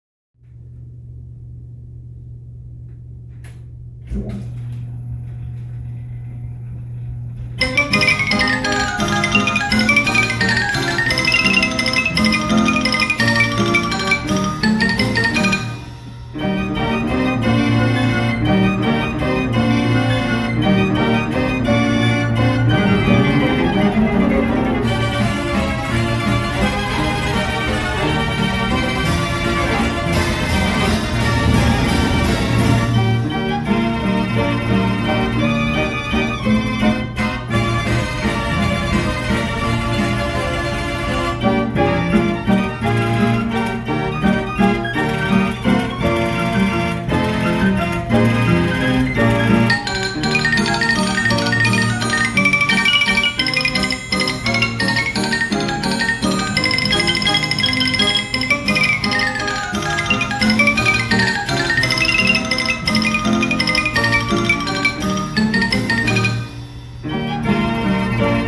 Built around the frame of a partially completed upright piano, the instrumentation includes Piano, with Mandolin attachment, 37 violin pipes, 18 Cello pipes, Xylophone, Orchestra Bells, Drums, Cymbal, Tambourine, Castanets, and Triangle, all controlled by its original Philips 6-roll changer.
Wurlitzer-28B-Orchestrion.mp3